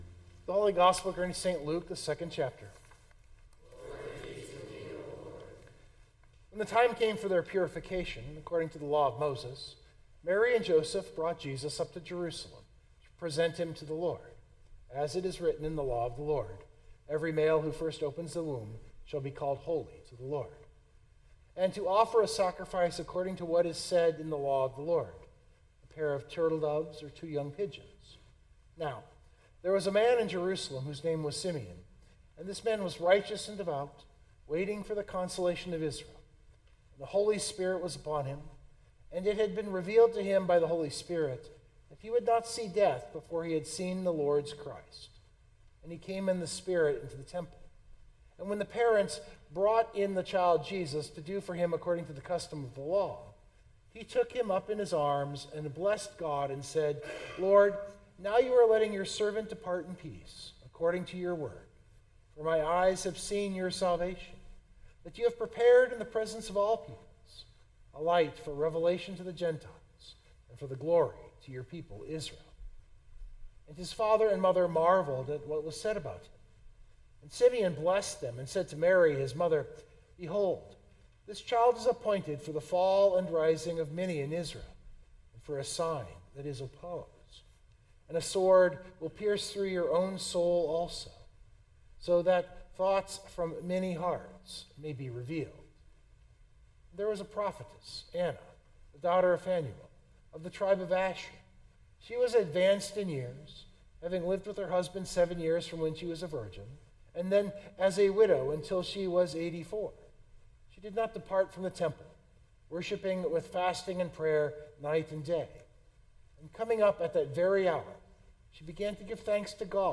That is what this sermon for Christmas develops.